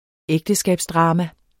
Udtale [ ˈεgdəsgabsˌdʁɑːma ]